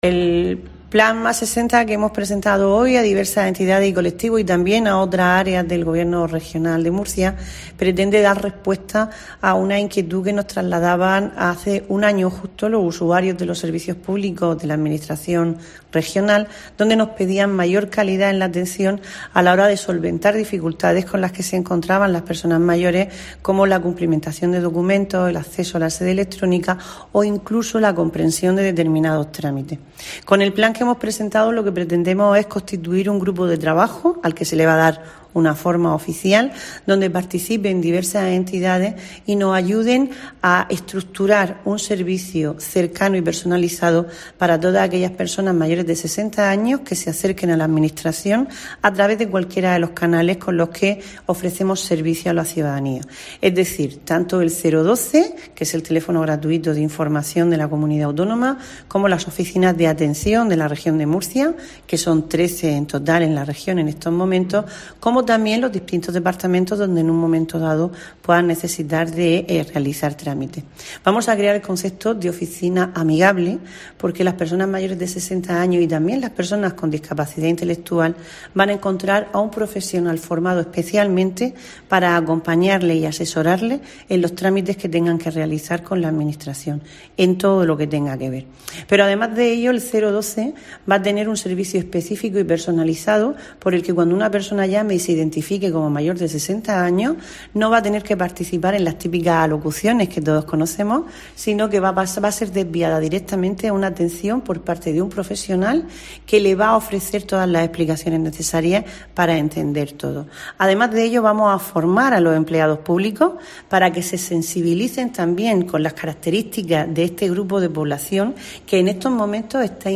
Isabel Franco, vicepresidenta y consejera de Transparencia, Participación y Cooperación